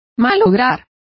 Also find out how malograsteis is pronounced correctly.